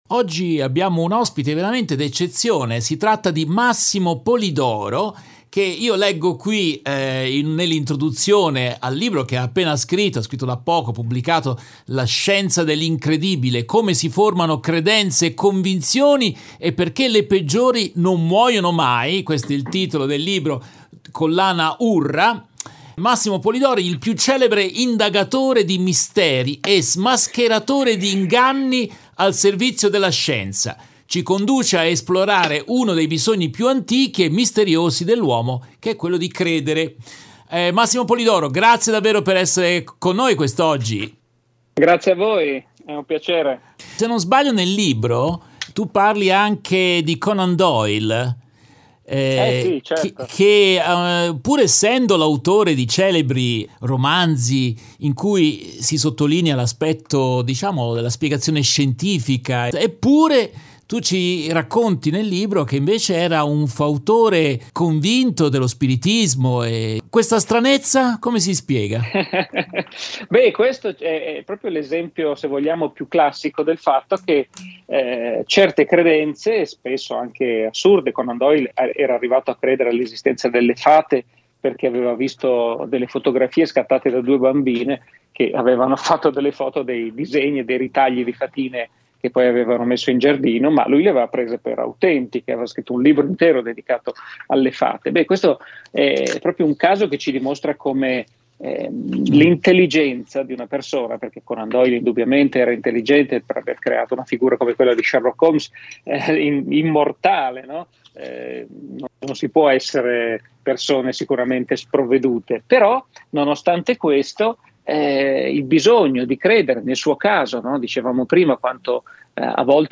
Nel corso della diretta RVS del 19 aprile 2023